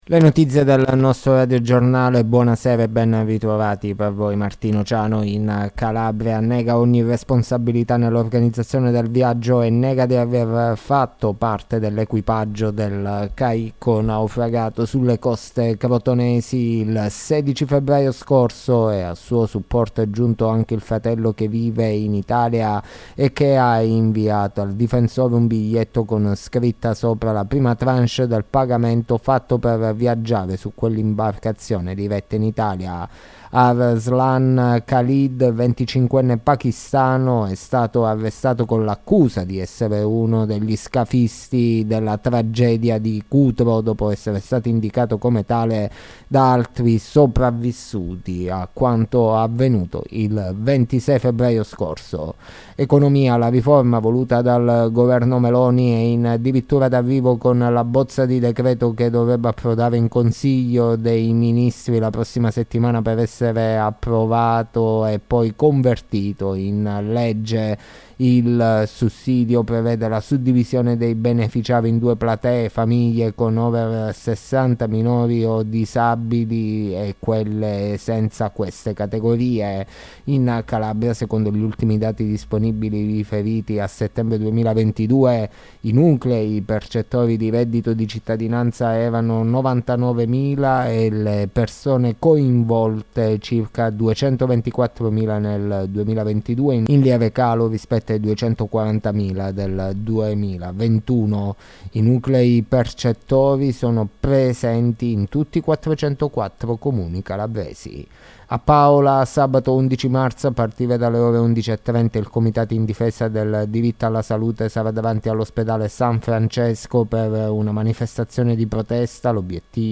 LE NOTIZIE DELLA SERA DI LUNEDì 06 MARZO 2023